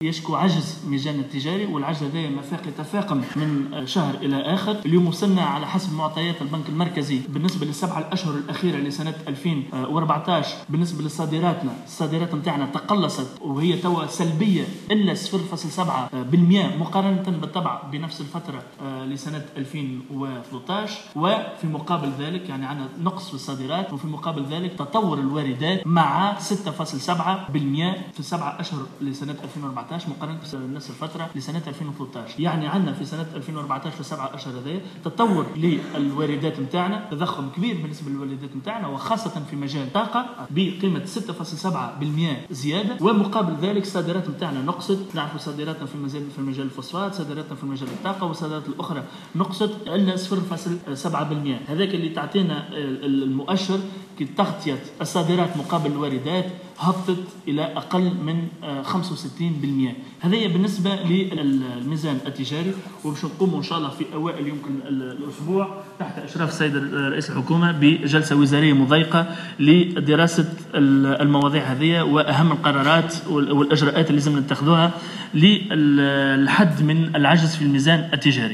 أكد الناطق الرسمي باسم رئاسة الحكومة نضال الورفلي إثر المجلس الوزاري الذي انعقد اليوم الأربعاء أن عجز الميزان التجاري قد تفاقم ليصل الى حدود 7 بالمائة،خلال السبعة أشهر الأخيرة من 2014 وذلك جراء تقلص الصادرات بنسبة 0.7 بالمائة وتضخم الواردات، خاصة في مجالي الطاقة والمواد الغذائية الأساسية .